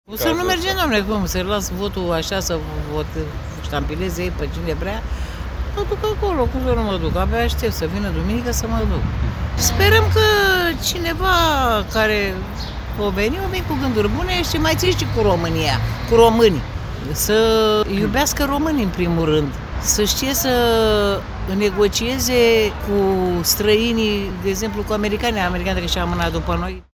Pe străzile Bucureștiului, o doamnă care se odihnea pe o bancă ne-a declarat că așteaptă cu entuziasm să-și exercite dreptul la vot.
O femeie își dorește ca viitorul președinte „să știe să negocieze cu străinii”
02mai-12-Vox-merg-la-vot.mp3